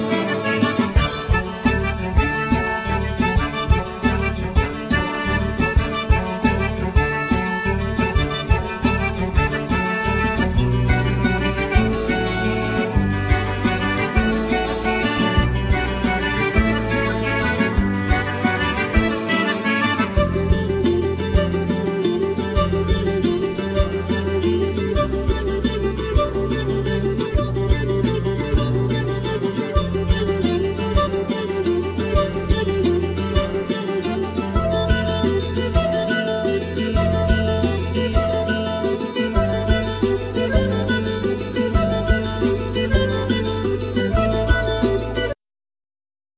Accordion
Flute
Mandolin
Cello